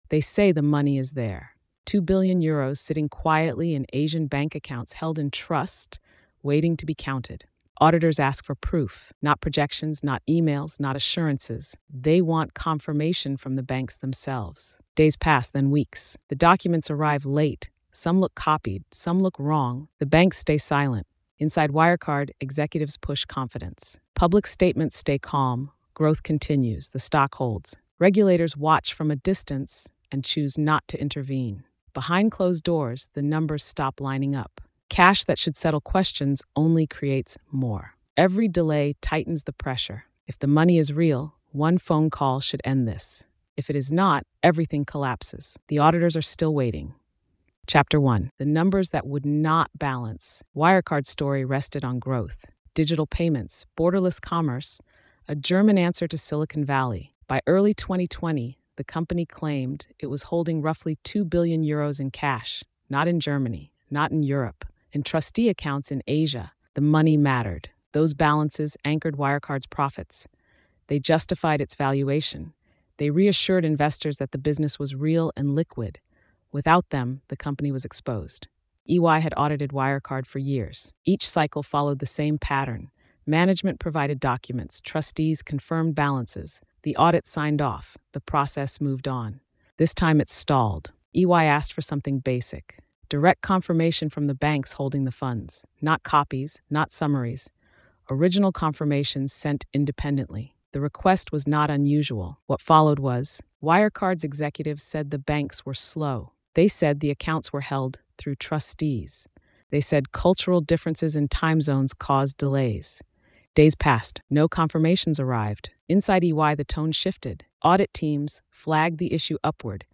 The Vanishing Billions: Wirecard’s Phantom Cash is a hard-edged investigative narrative that reconstructs the collapse of Wirecard, once celebrated as Germany’s fintech champion. The story follows the moment auditors demand proof of billions in claimed Asian bank accounts and discover that the money cannot be confirmed, setting off a chain reaction that exposes forged documents, systemic oversight failures, and a fraud that thrived on trust rather than verification. Told with journalistic restraint and rising pressure, the series focuses on evidence, institutional blind spots, and the human and market consequences of believing numbers that were never real.